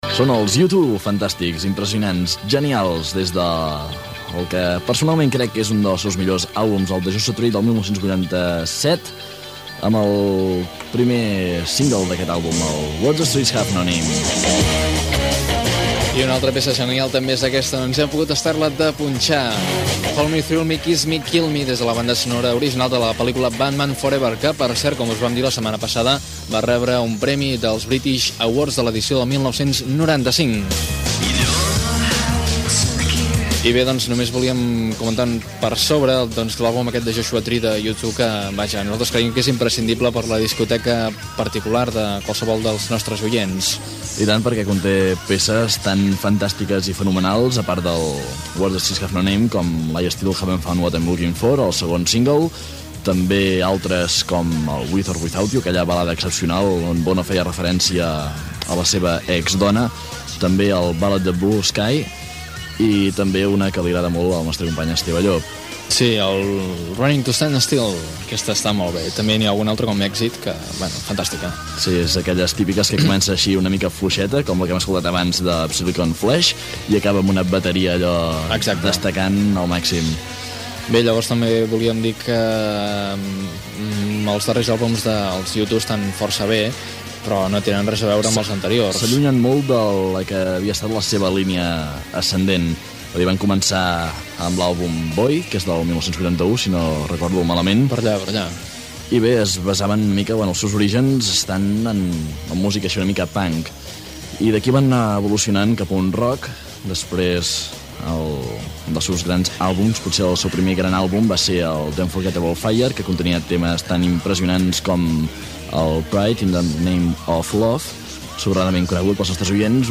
Magazine de nit
Comentaris sobre temes musicals del grup U2, indicatiu del programa, comentari sobre les eleccions Generals del 2 de març de 1996, tema musical i comiat
Entreteniment
FM